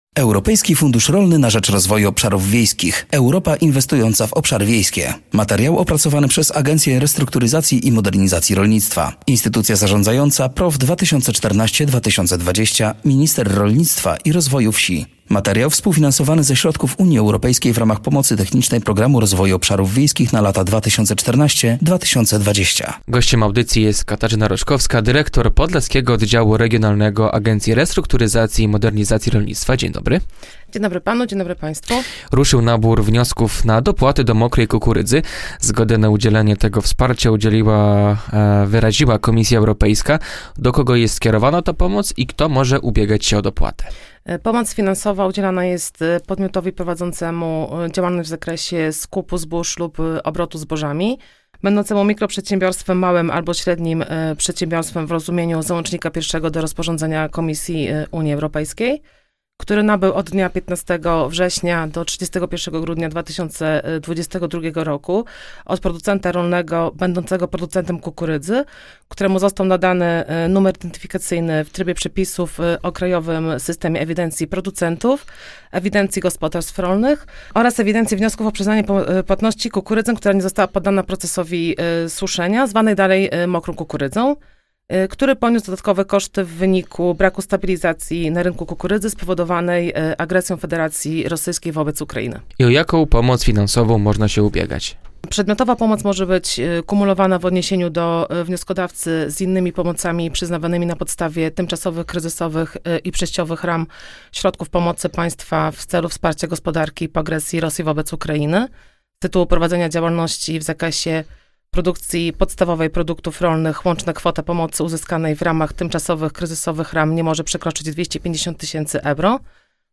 Odpowiedzi na te pytania można usłyszeć w rozmowie